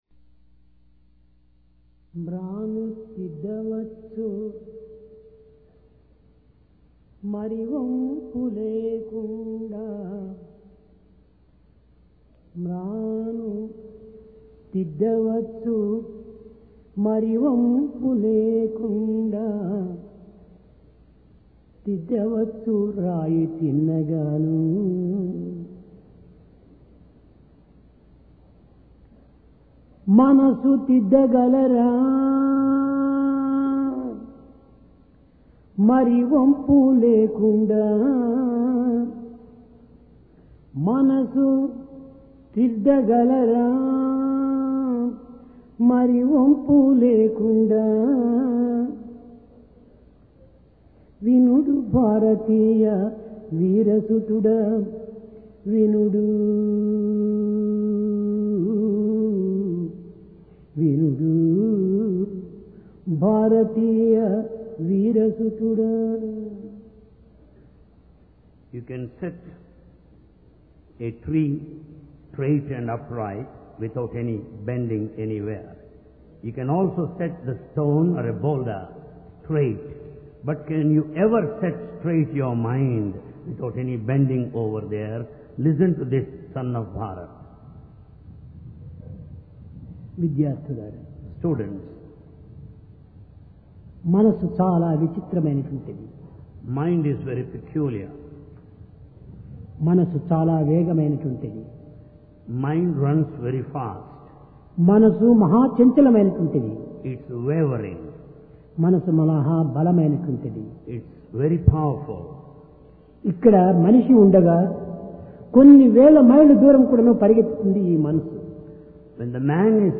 Sai Darshan Home Date: 23 Jun 1996 Occasion: Divine Discourse Place: Prashanti Nilayam Conquer The Mind You can straighten up a tree, you can straighten even a rock, but can you straighten a mind?